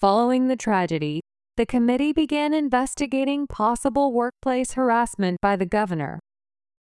１文ずつ区切ったスロー音声を再生し、文字を見ずにリピートしましょう。